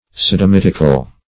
Search Result for " sodomitical" : The Collaborative International Dictionary of English v.0.48: Sodomitical \Sod`om*it"ic*al\, a. Pertaining to, or of the nature of, sodomy.
sodomitical.mp3